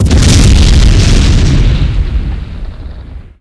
explosion.xpl03.wav